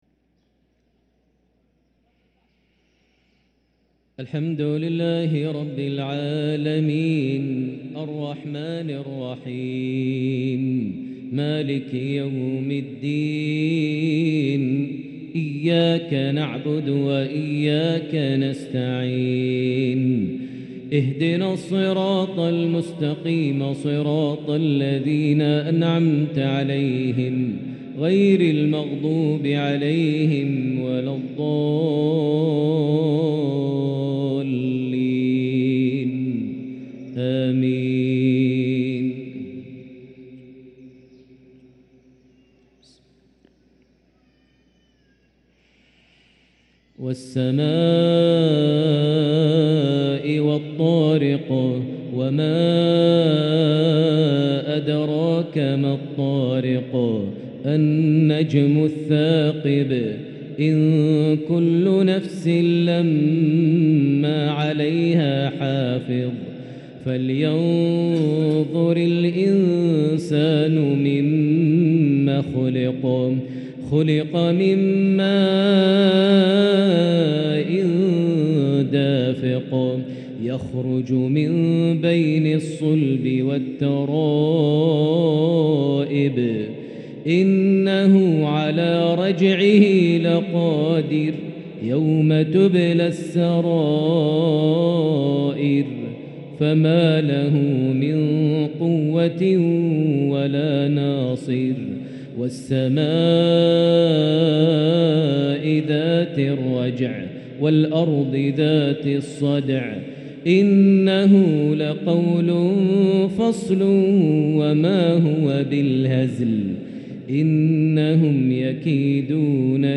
ترتيل عذب لسورتي لسورتي الطارق والعاديات | مغرب الأربعاء 2-8-1444هـ > 1444 هـ > الفروض - تلاوات ماهر المعيقلي